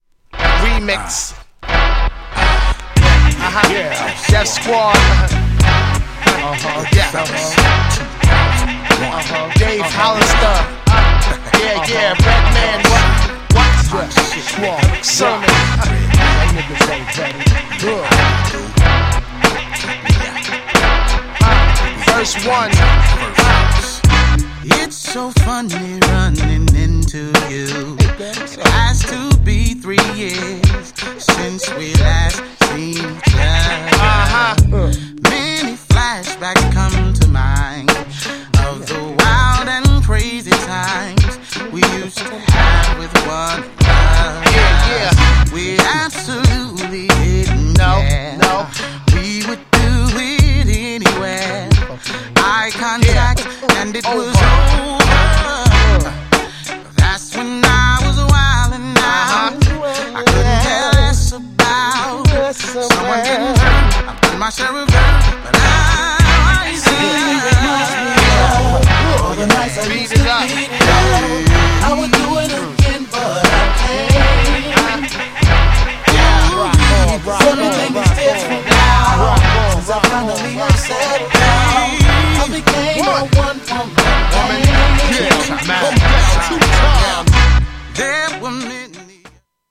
GENRE R&B
BPM 91〜95BPM